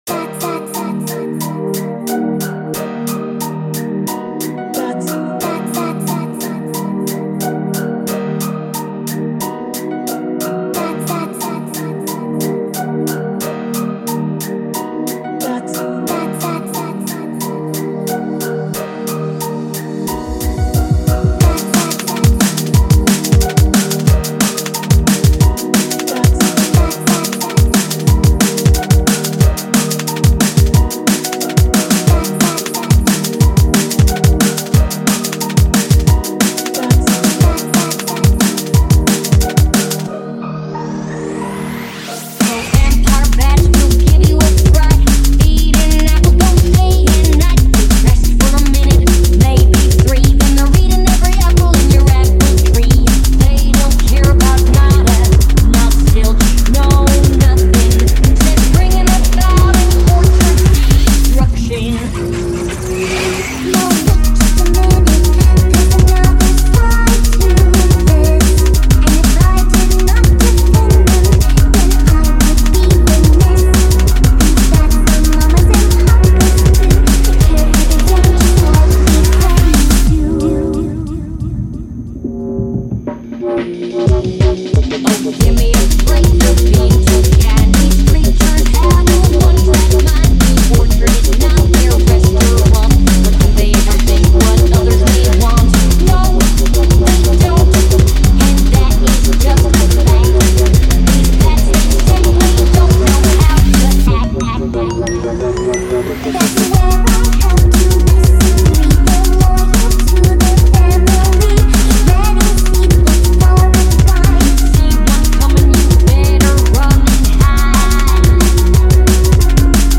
smooth DnB garage
genre:remix